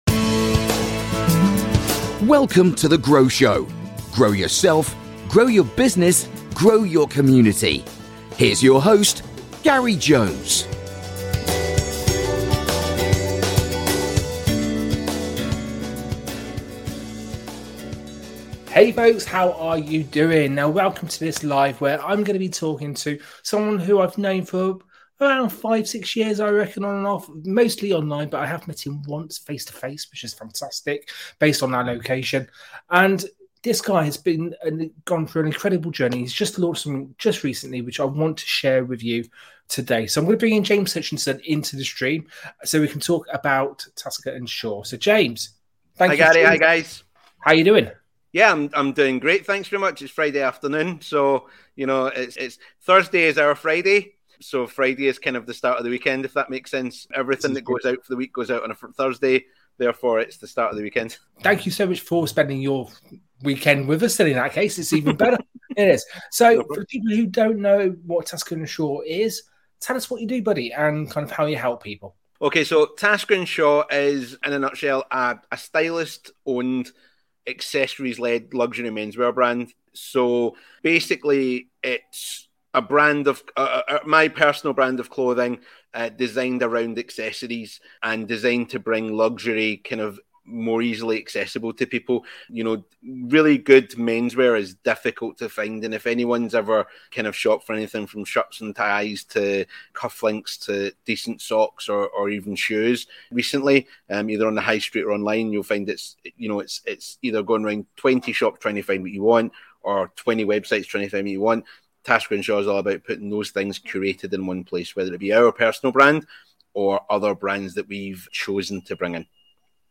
This was originally a Facebook Live with our close Facebook Group called Grow Community.